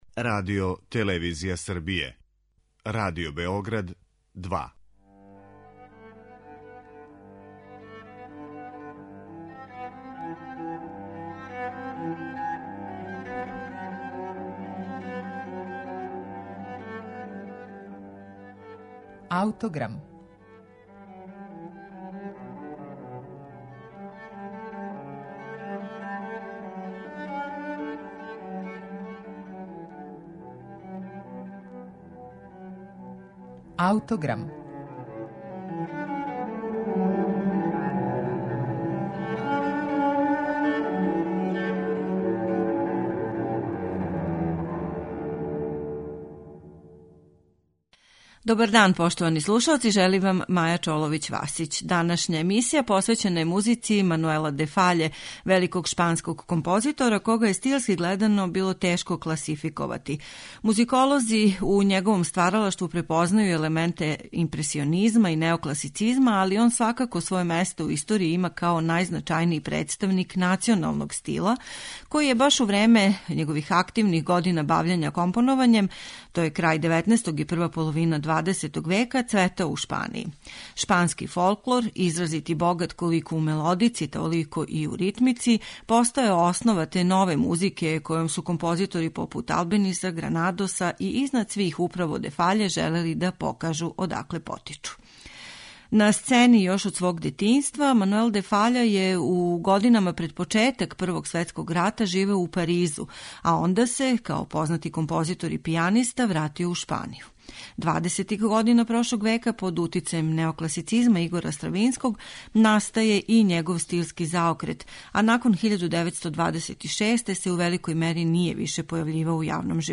балет пантомима